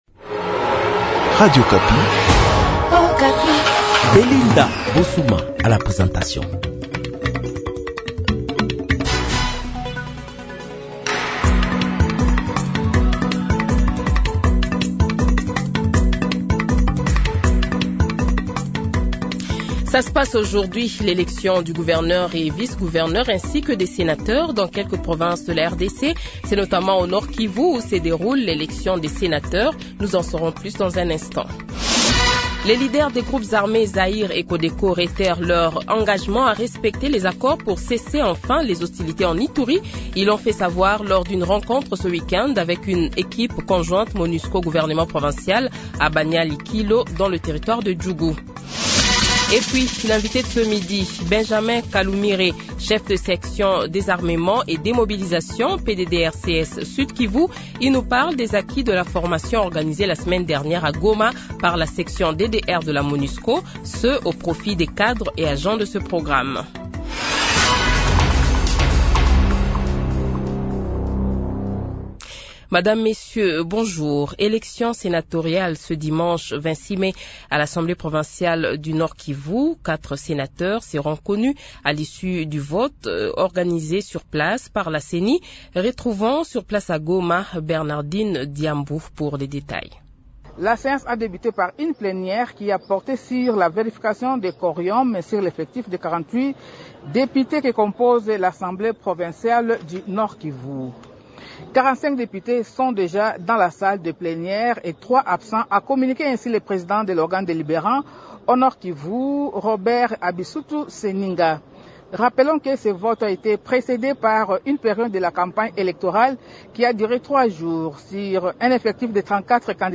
Le Journal de 12h, 26 Mai 2024 :